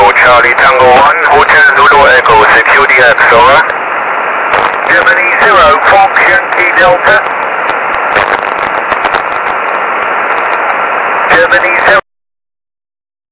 Five minutes later the opening started with lots of QSB, opening was very spotty.